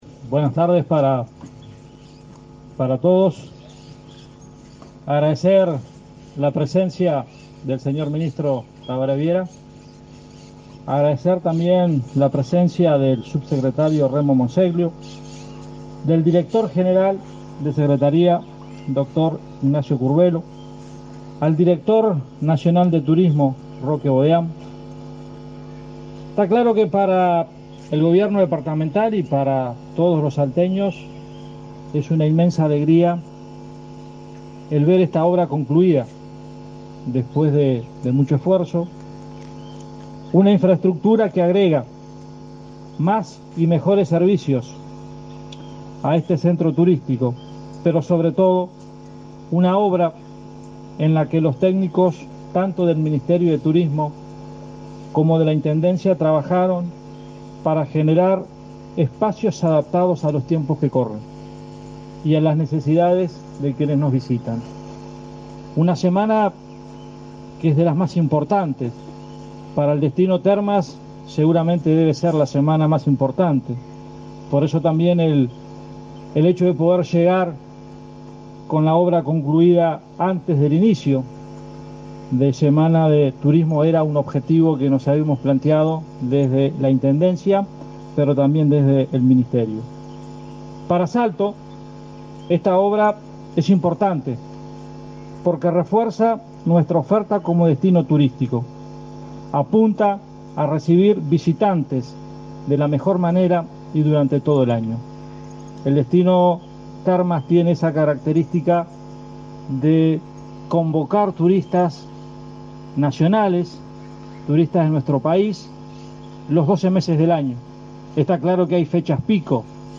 Conferencia de prensa por inauguración de obras en Termas del Daymán